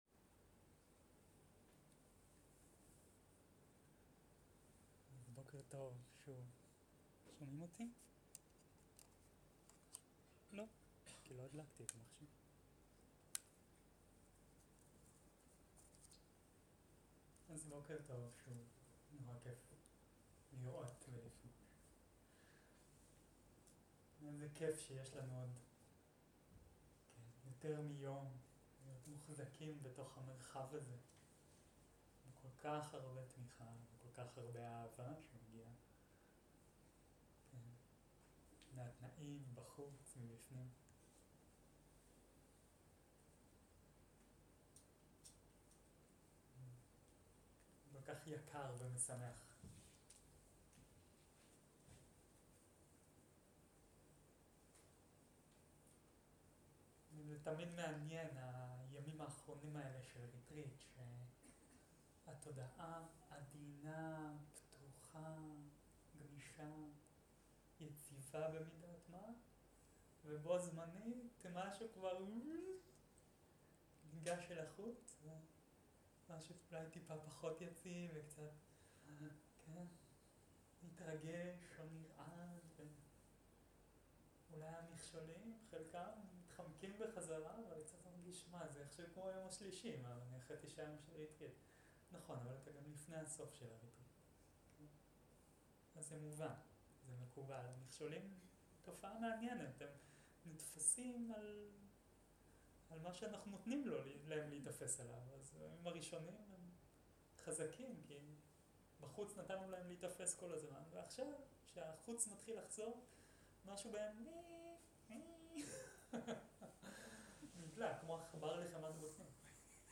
Dharma type: Guided meditation